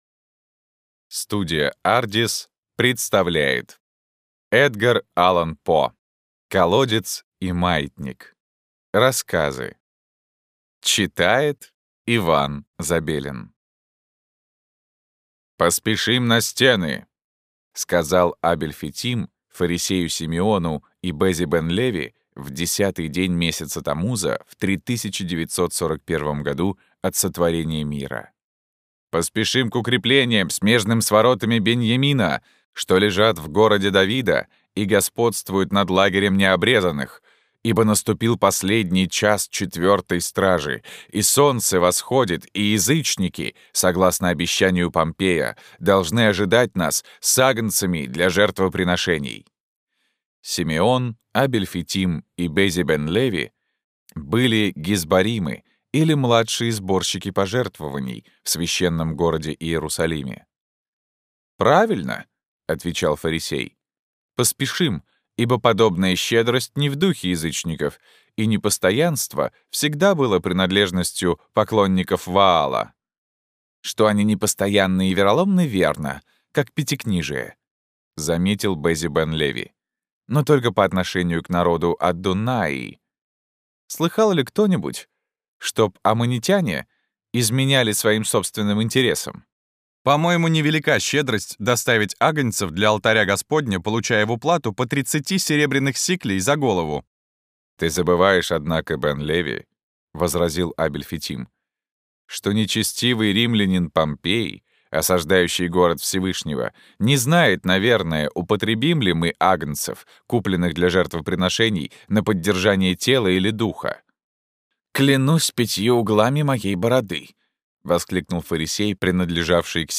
Аудиокнига Колодец и маятник.